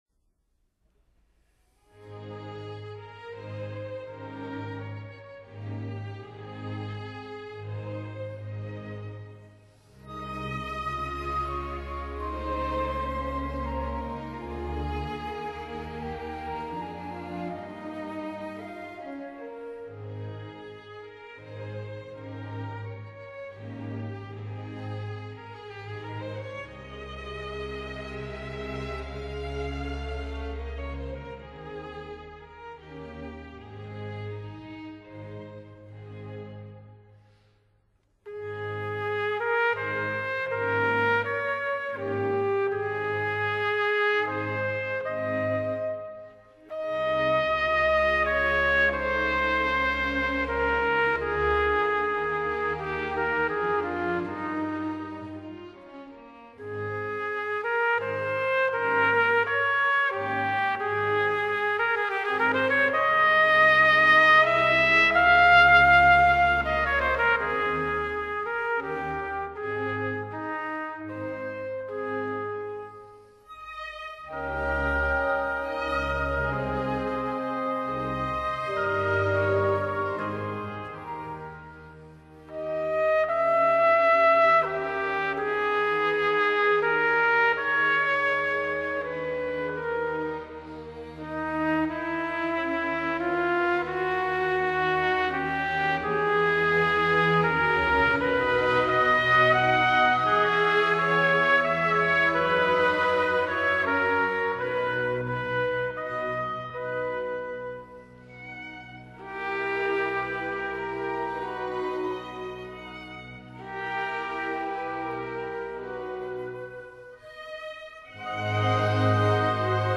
trumpet
horn
trombone